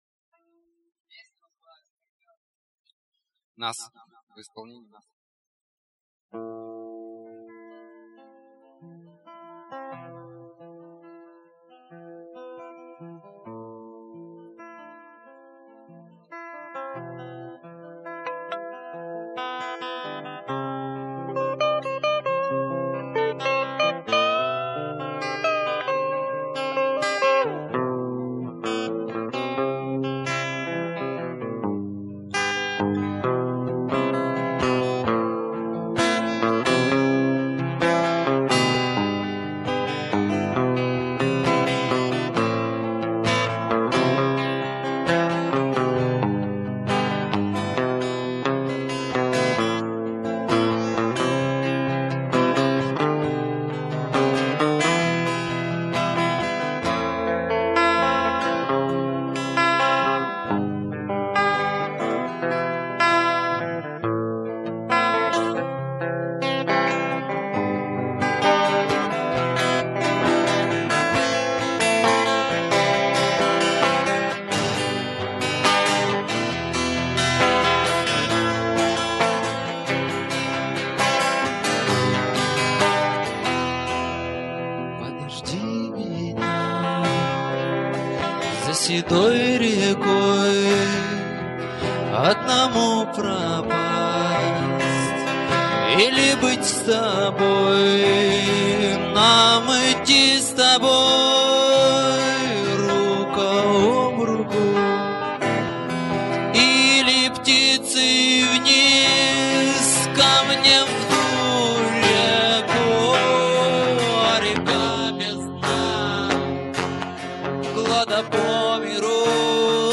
(3,68Mb, 96Kbps, Live, 2003)